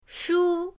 shu1.mp3